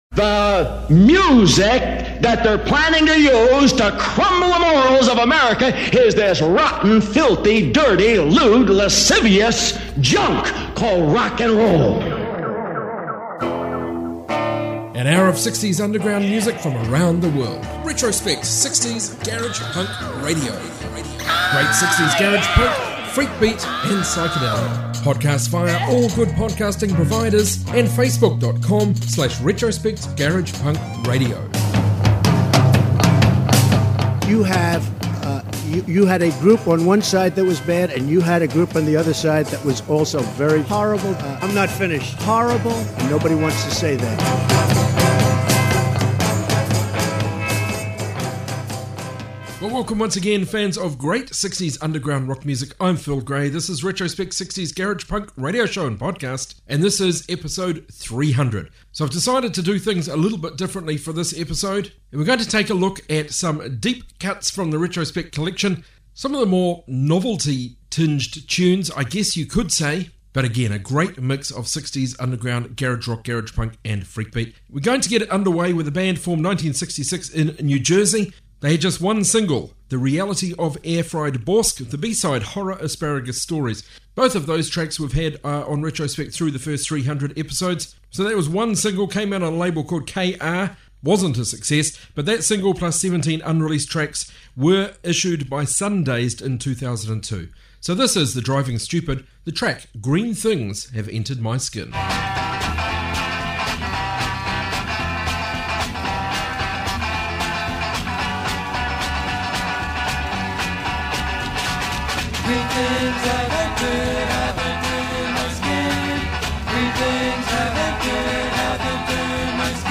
60s garage from all over